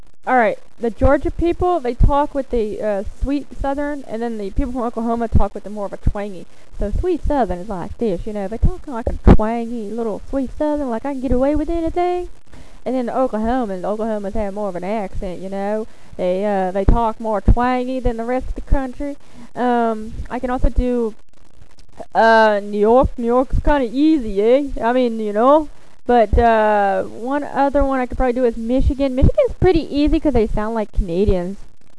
Here are a list of Accents and Impersonations I can do...
Southern And Yankee
southernyankee.wav